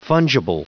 Prononciation du mot fungible en anglais (fichier audio)
Prononciation du mot : fungible